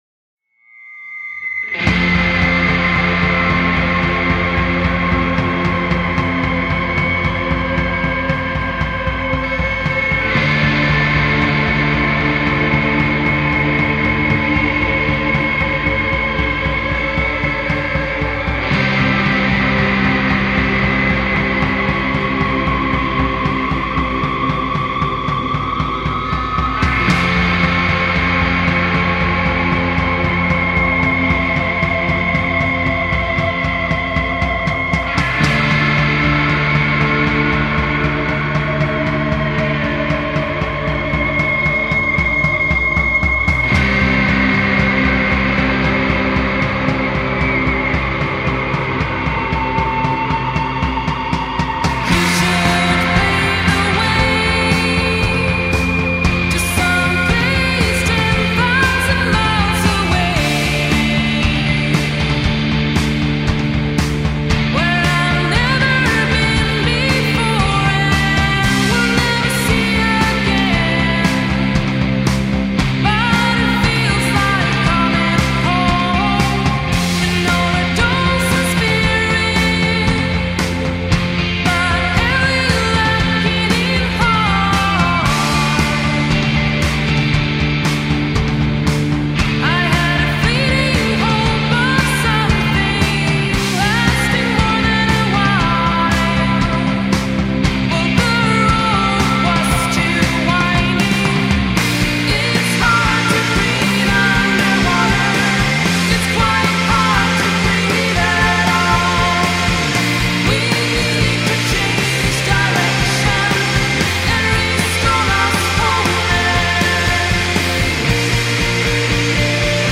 indie rock